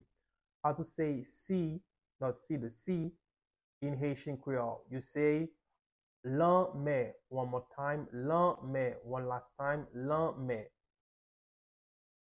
Listen to and watch “lanmè” audio pronunciation in Haitian Creole by a native Haitian  in the video below:
15.How-to-say-sea-in-Haitian-Creole-–-lanme-with-pronunciation.mp3